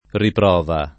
vai all'elenco alfabetico delle voci ingrandisci il carattere 100% rimpicciolisci il carattere stampa invia tramite posta elettronica codividi su Facebook riprova [ ripr 0 va ] (ant. ripruova [ ripr U0 va ]) s. f.